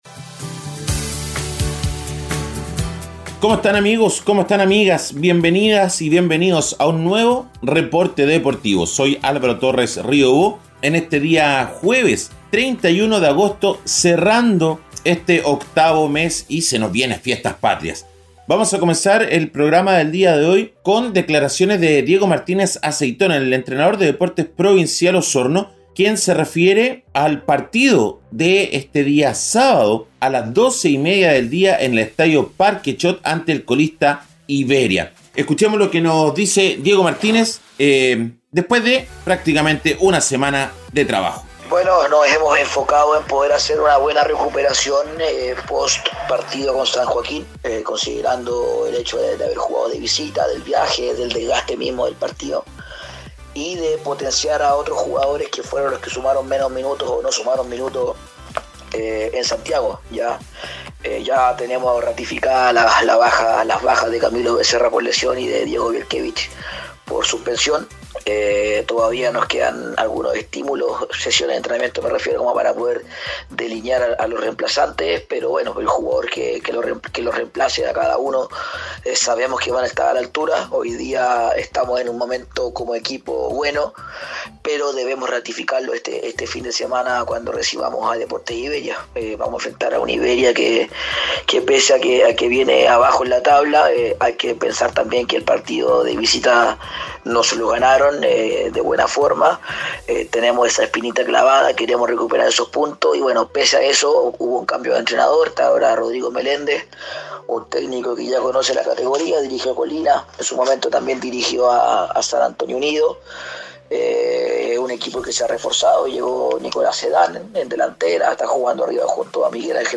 En este episodio, les traemos un breve reporte con las "deportivas" más destacadas de las últimas 24 horas.